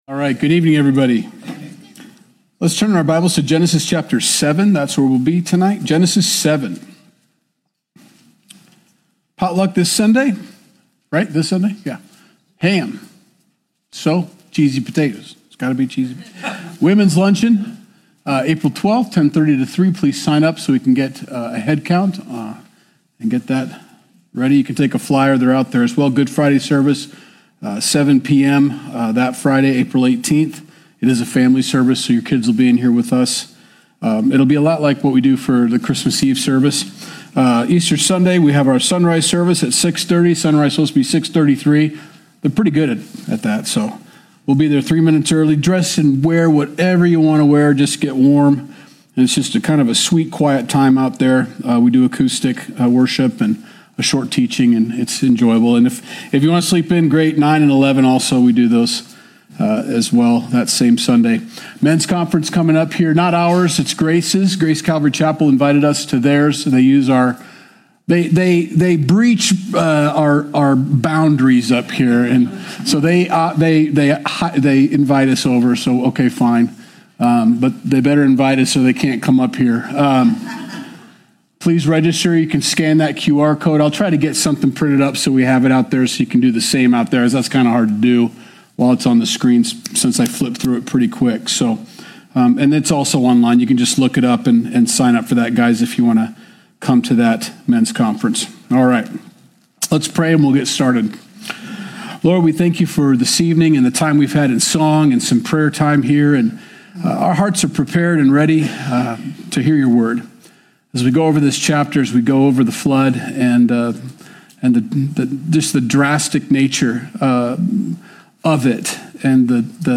Sunday Message - March 2nd, 2025